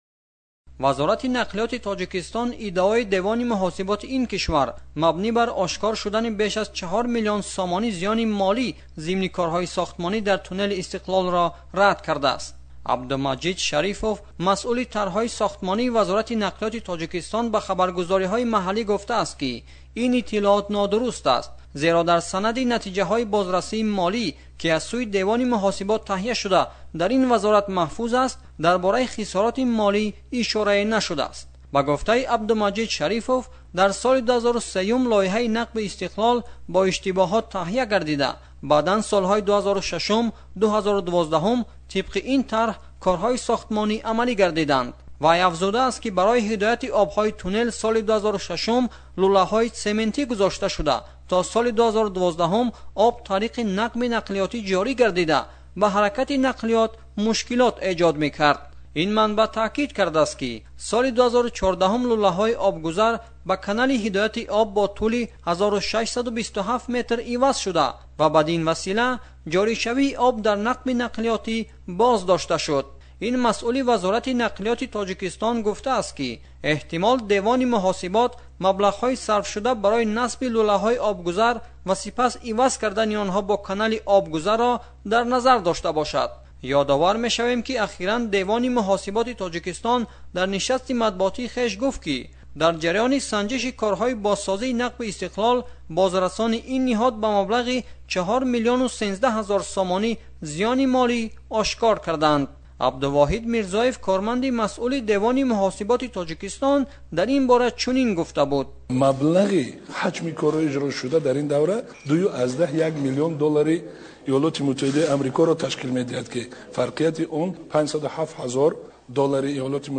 Гузориши